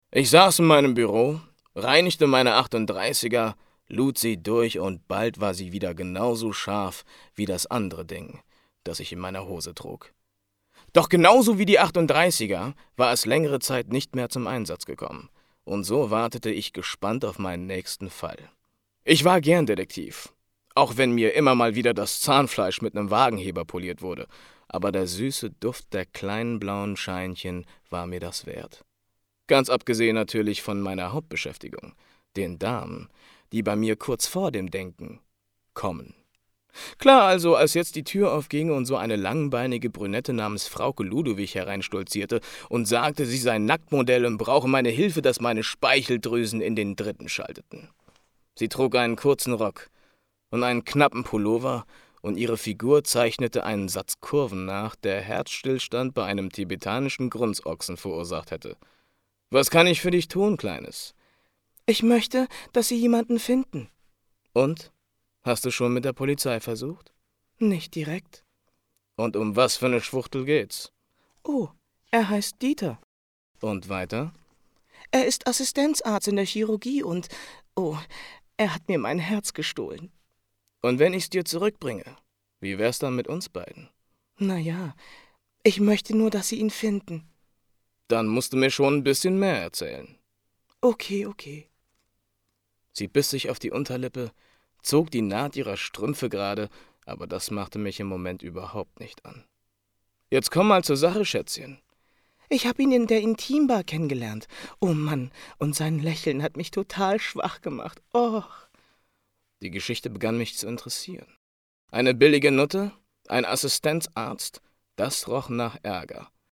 Theaterschauspieler und Sänger
Sprechprobe: eLearning (Muttersprache):